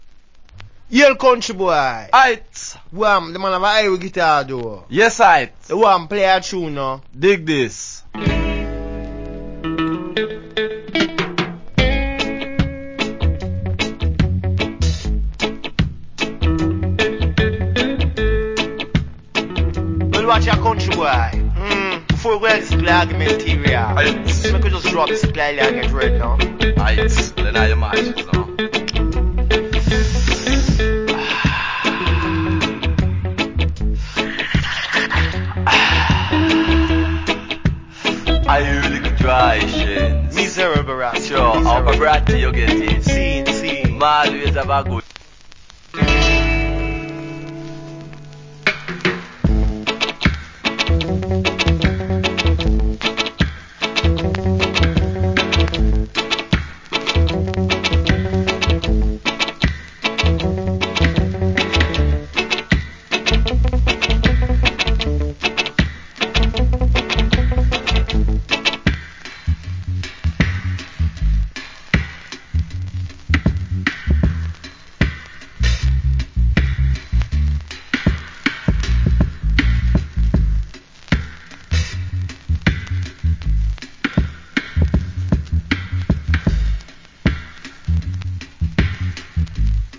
Nice DJ And Instrumental.